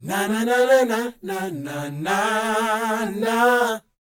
NA-NA A BD-L.wav